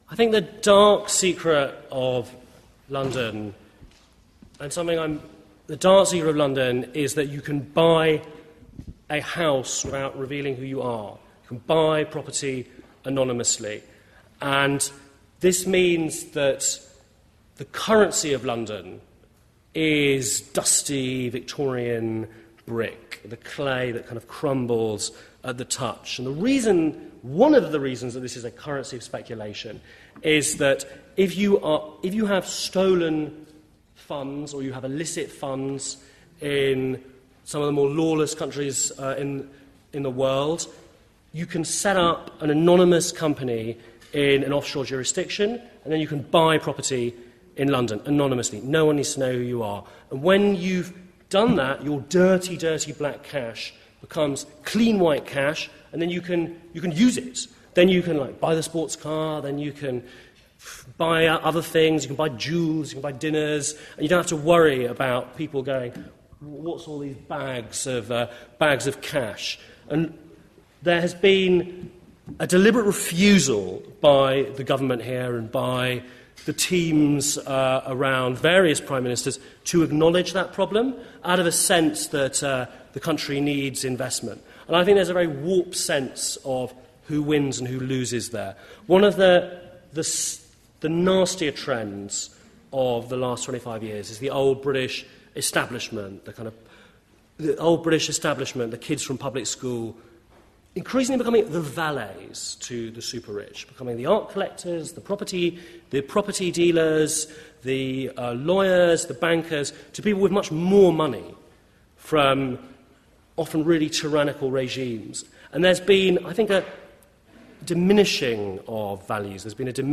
Clip from a recent public lecture and Q&A at the London School of Economic